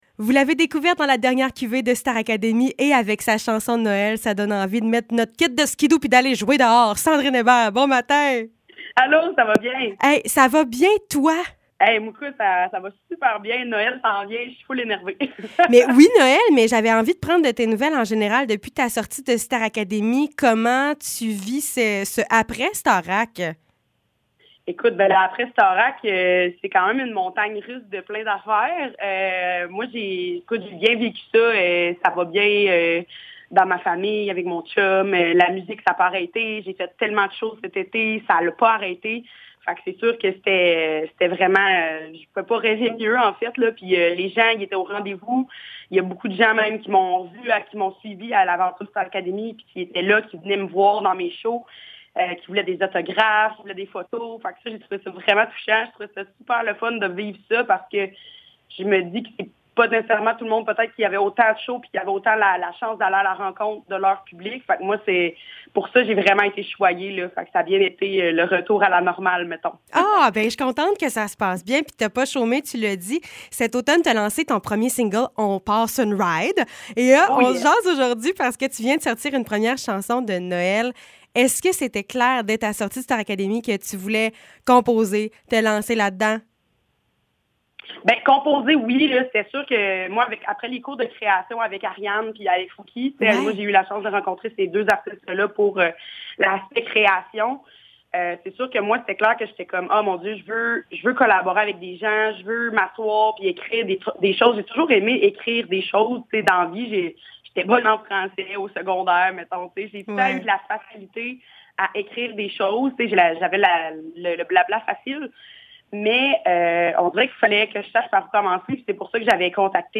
Entrevue avec la chanteuse